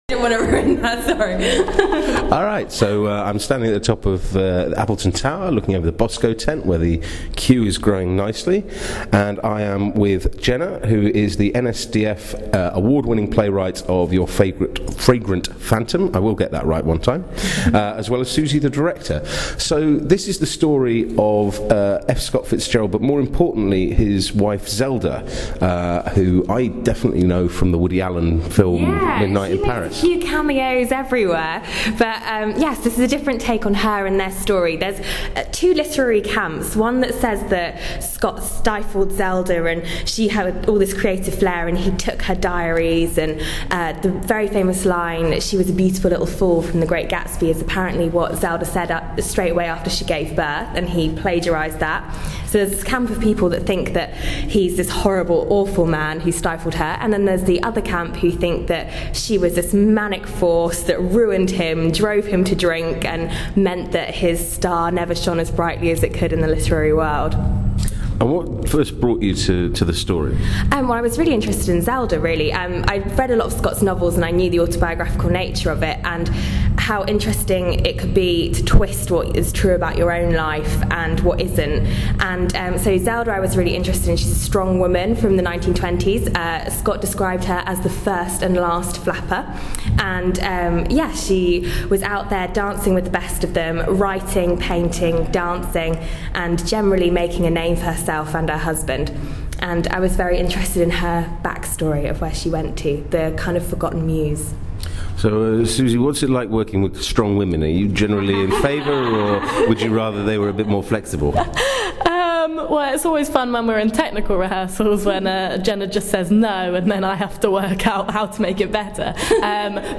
talks to the team behind Your Fragrant Phantom.